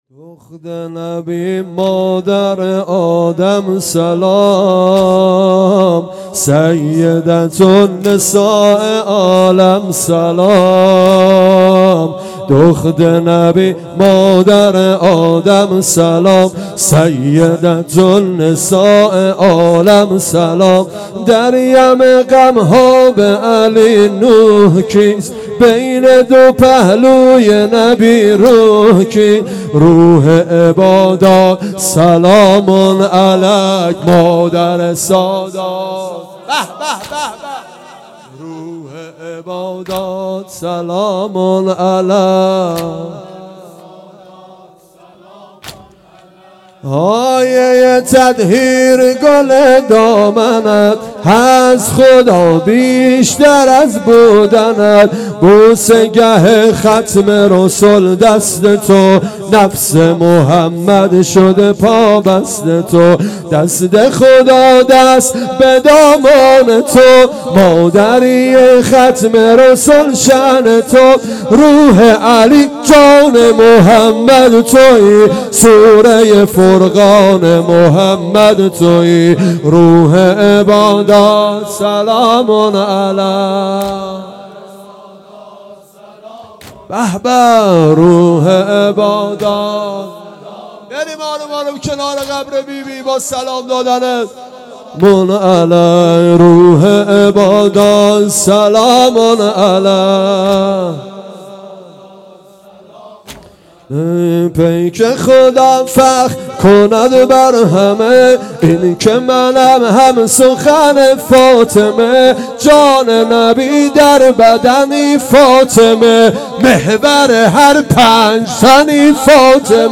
دخت نبی مادر ادم سلام _ واحد
شهادت حضرت فاطمه زهرا سلام الله علیها _ شب دوم _ فاطمیه دوم